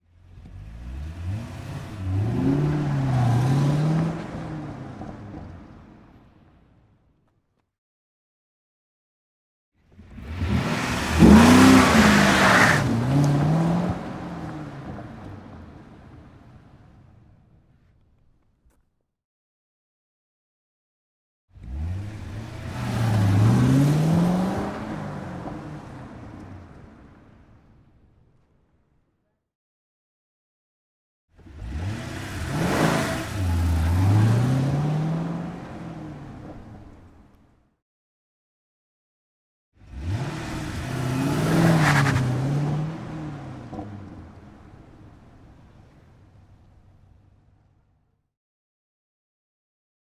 随意 " 汽车性能车迅速拉开距离 大转数剥离出发动机轰鸣声接近
描述：汽车性能车迅速拉开大转速剥离发动机咆哮更近
标签： 性能 汽车 汽车 剥离
声道立体声